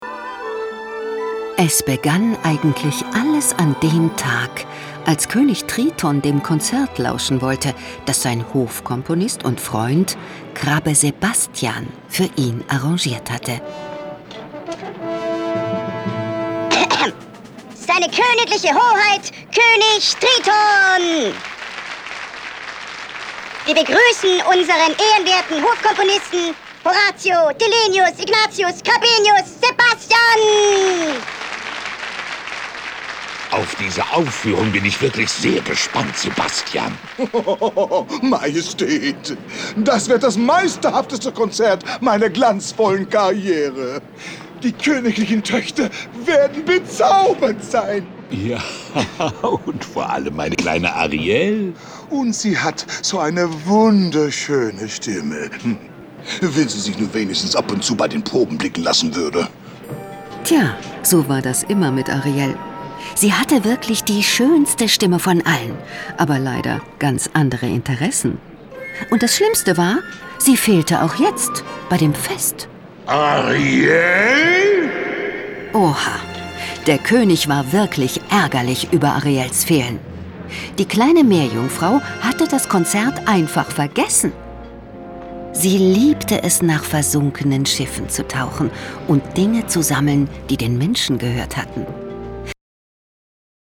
Hörspiel mit Liedern